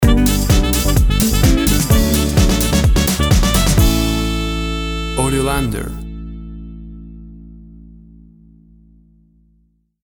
Tempo (BPM) 130